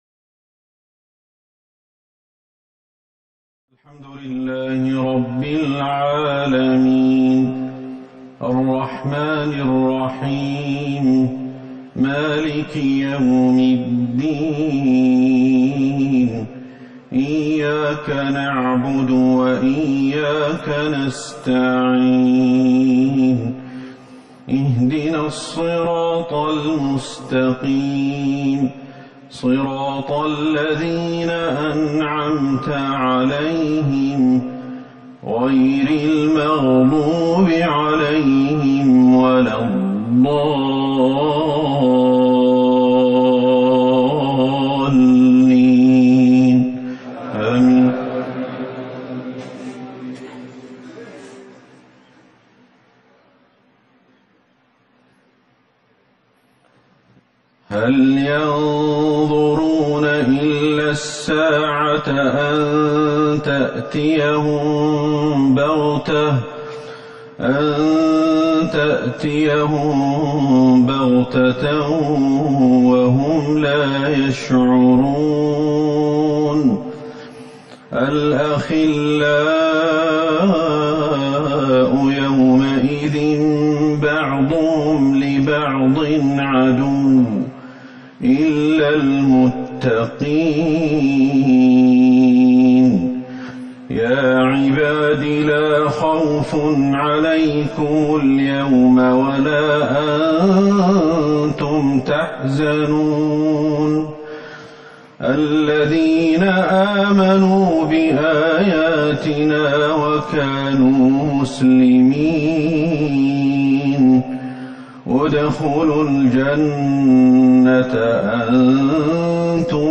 صلاة العشاء ١٠ جمادى الآخرة ١٤٤١هـ من سورة الزخرف | Isha prayer 4-2-2020 from Surah Az-Zukhruf > 1441 هـ > الفروض - تلاوات الشيخ أحمد الحذيفي